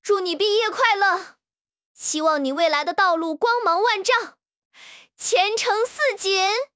Text-to-Speech
Spark TTS finetuned in genshin charactors voices.